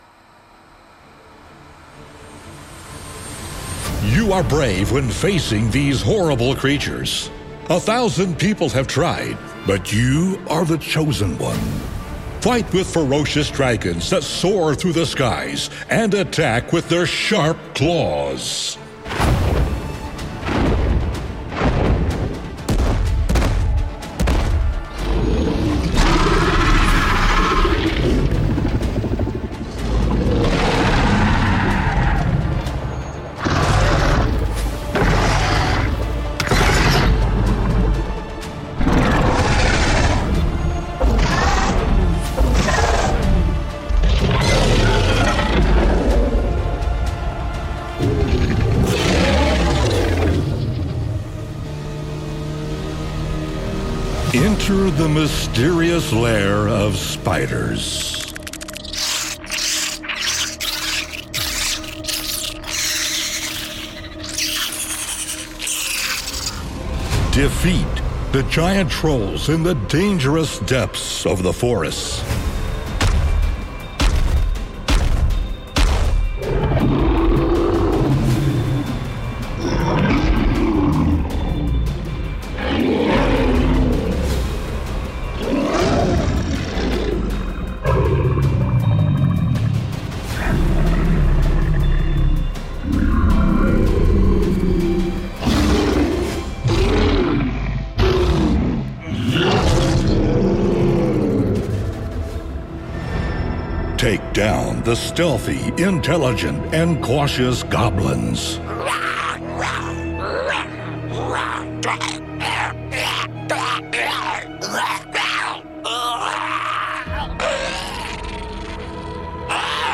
奇幻游戏飞龙巨魔怪物嘶吼音效素材 Khron Studio Monster Library Vol 3
该音效库专注于重现各种幻想世界中的著名怪物声音，包括龙、巨魔、哥布林和兽人等。总共提供168种声音，涵盖攻击、吼叫、脚步声、疼痛、死亡等多个类别，以及一些特殊能力（如飞行）的声音效果。
声道数：立体声